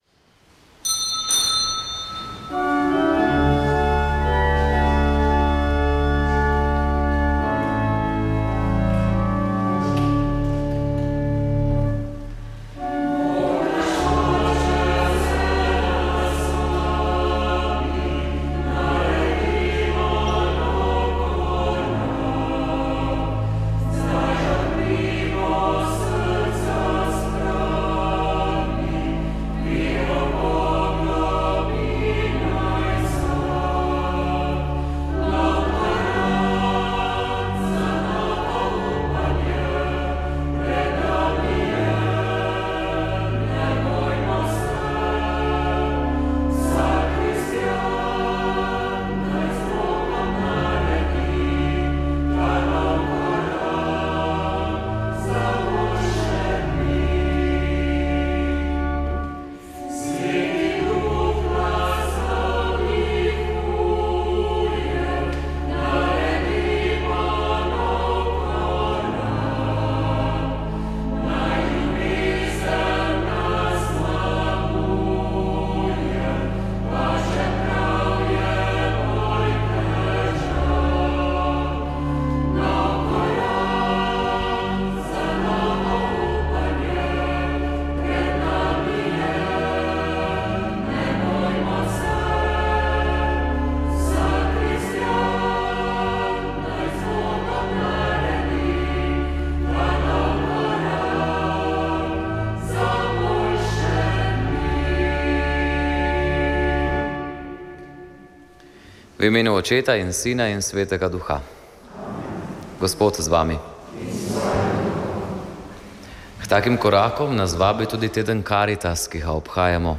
Sv. maša iz župnijske cerkve sv. Martina v Poljanah nad Škofjo Loko
Sveto mašo smo prenašali iz župnije Poljane nad Škofjo Loko.